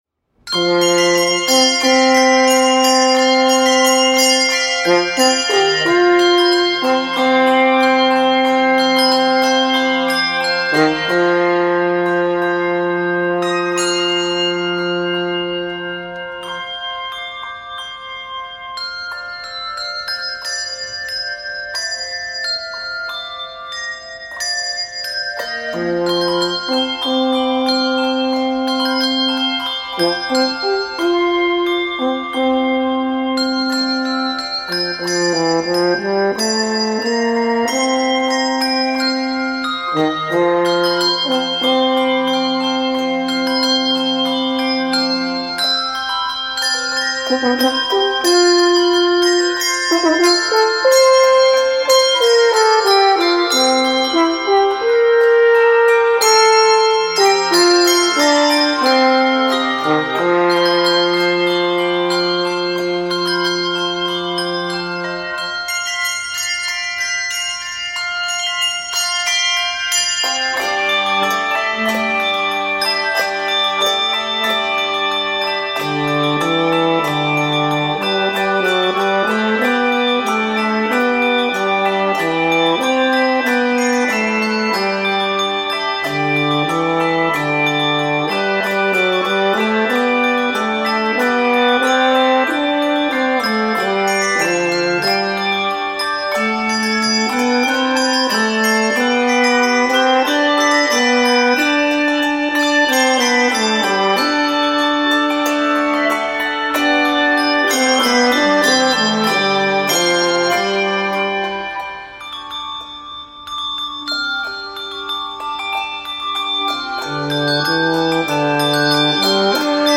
Arranged with optional Horn in F or Trombone
Known for his blending of handbells with solo instruments
majestic and accessible edition